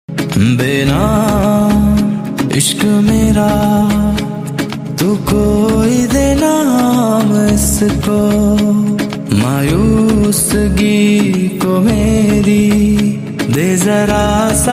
romantic Bollywood ringtone